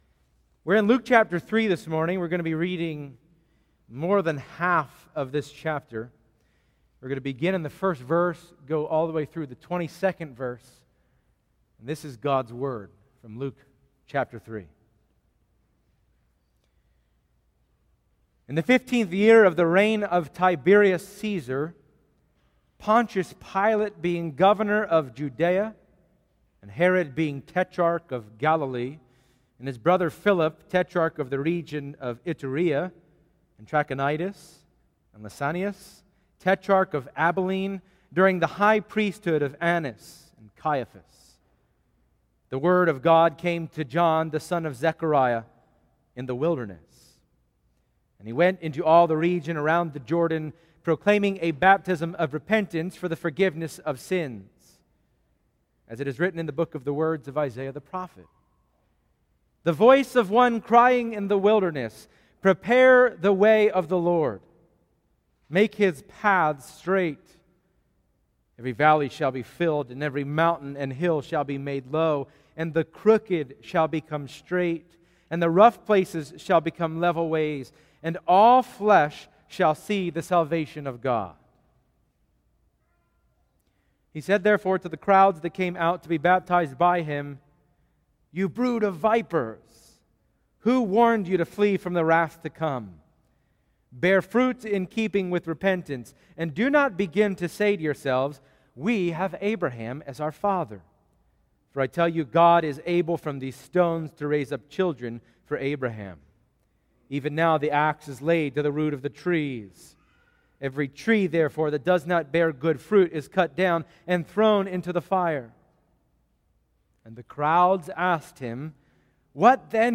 Passage: Luke 3:1-22 Service Type: Sunday Morning %todo_render% Download Files Bulletin « THE Question Luke 4:31-44 »